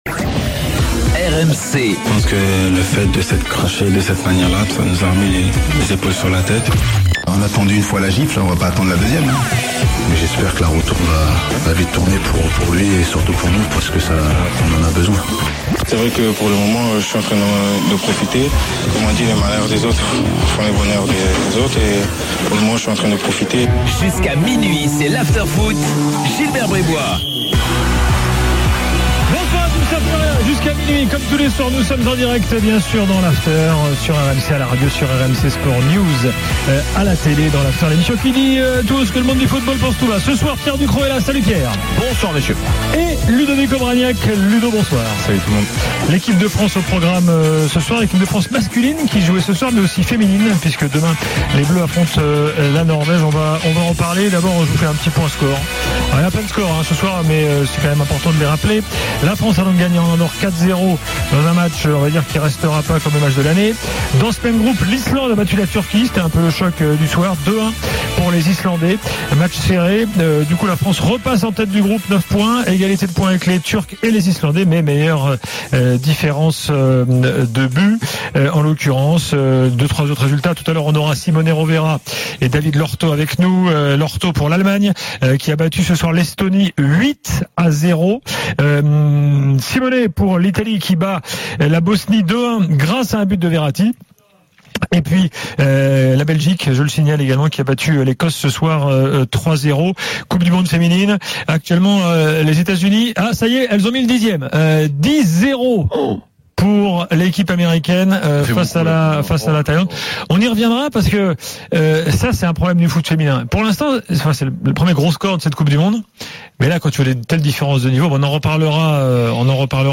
Après le match, avec Gilbert Brisbois, Pierre Ducrocq et Ludovic Obraniak, le micro de RMC est à vous !